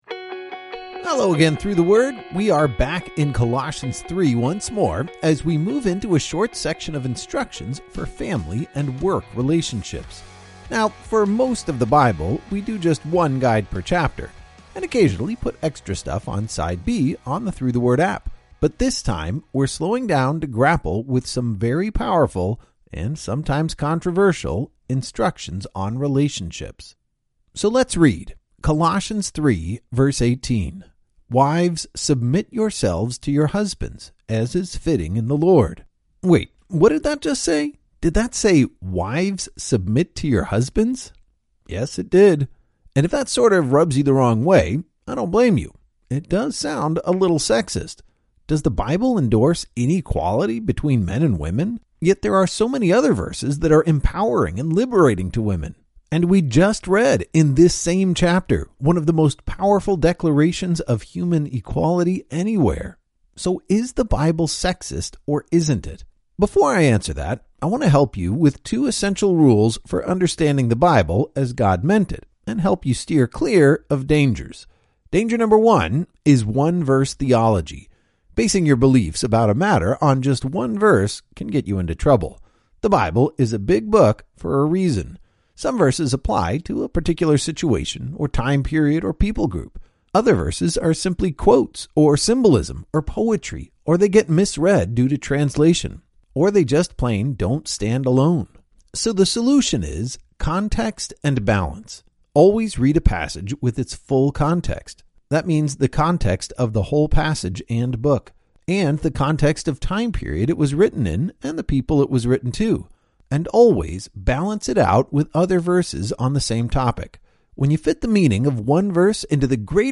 Every chapter brings new insights and understanding as your favorite teachers explain the text and bring the stories to life.